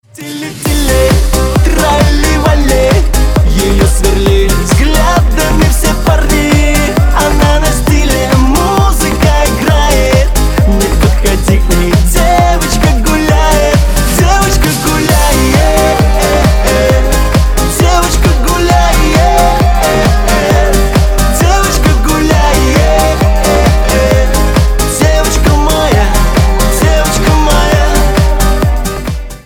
Веселые русские мотивы
Веселые мелодии
веселый мотив на звонок